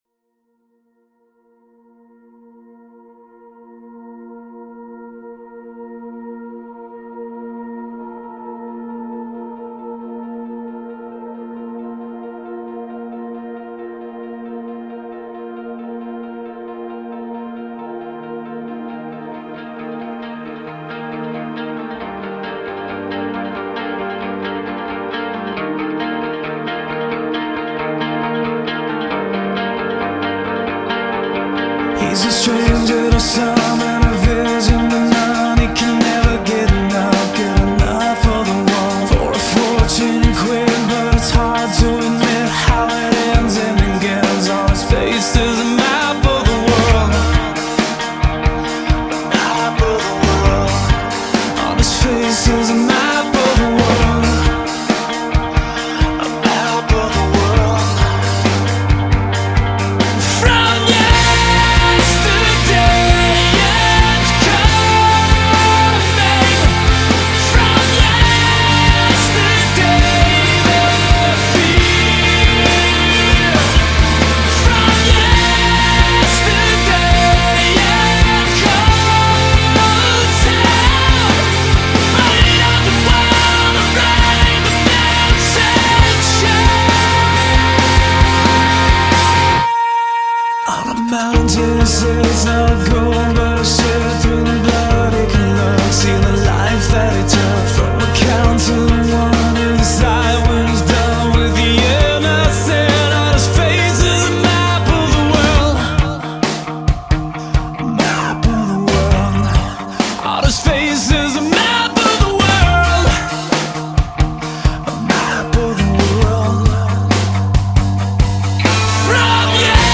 Категория: Rock & Metal